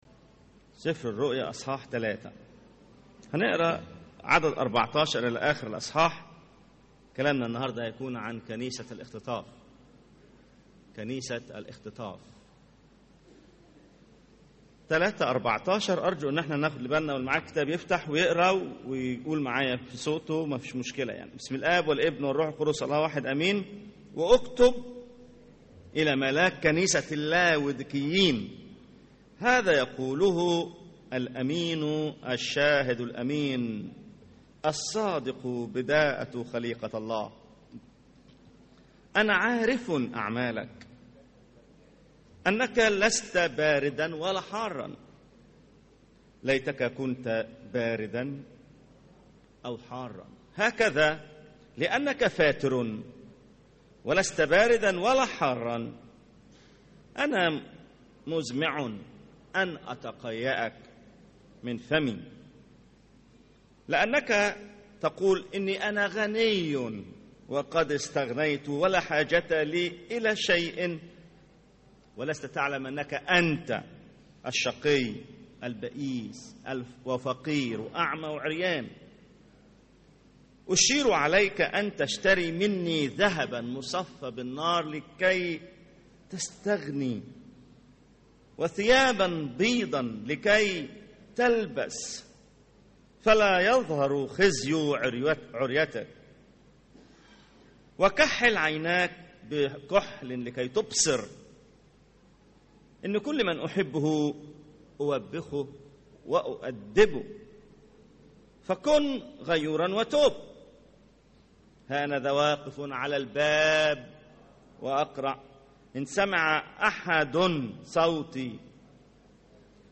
سلسلة من ثلاث عظات عن الاختطاف.. العظة الثانية – كنيسة الاختطاف